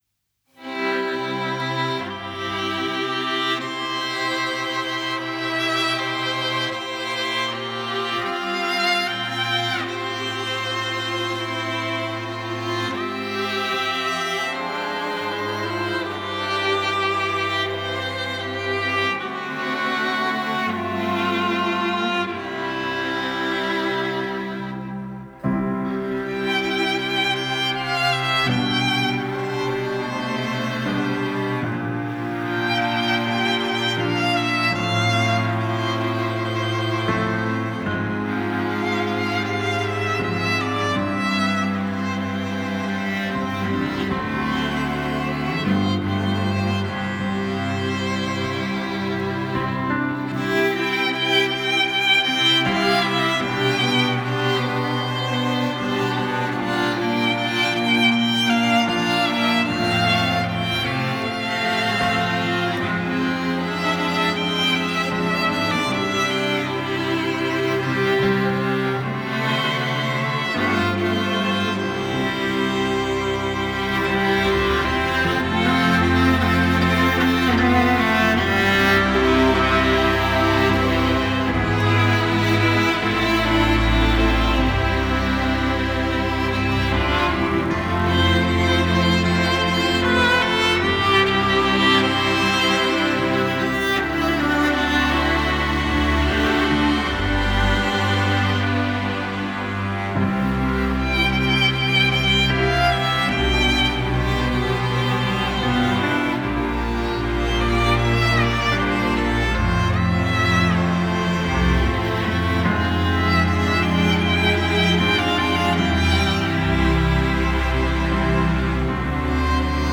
электронный струнный квартет из Лондона, Великобритания
Genre: Electronic, Classical